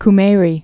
(k-mārē, -mā-rē)